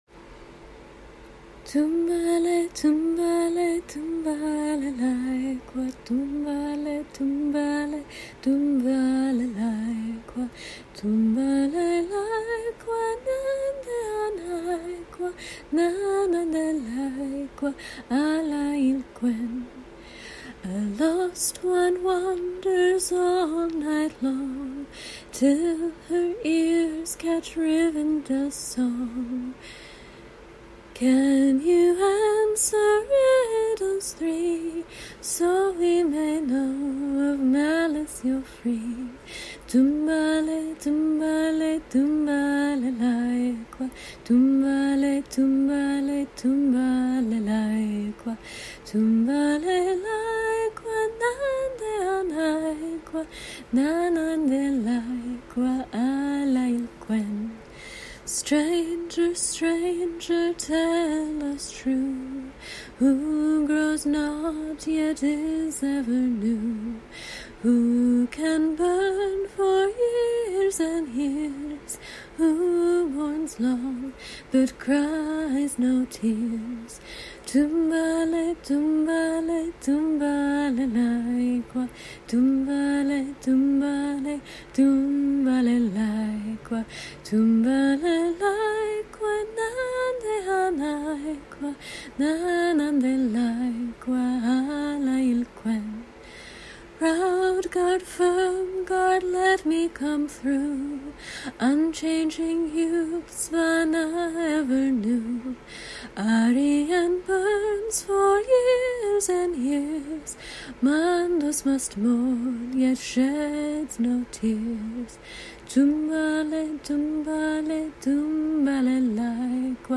To be sung to the traditional tune of "Tumbalalaika," as here.
A traditional riddle-song passing along received wisdom on how to find shelter at the Last Homely House.
Genre: Music